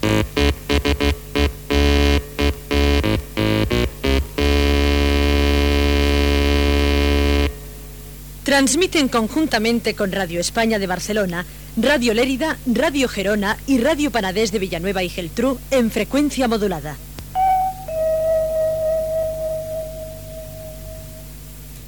Sintonia i indicatiu de transmissió conjunta